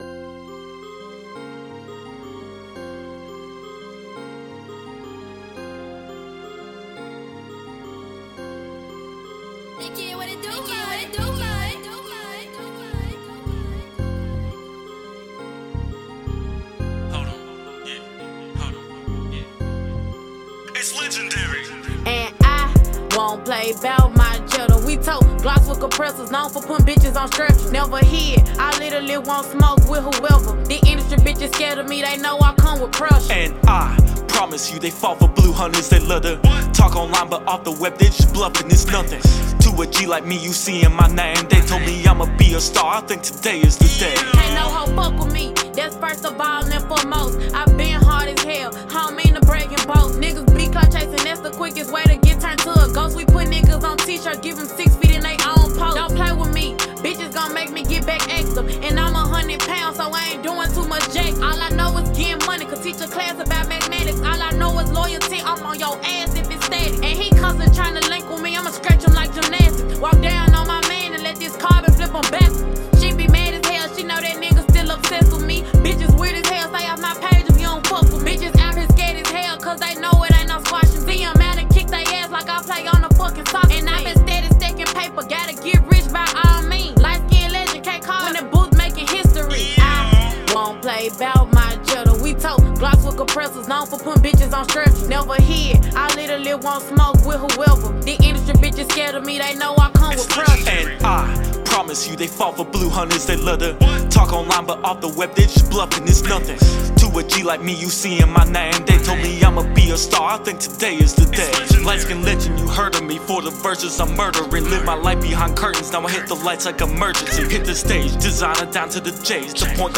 Rap(Music), Hip-hop, Popular music—Tennessee—Memphis, African Americans—Music, Memphis(Tenn.)—Social life and customs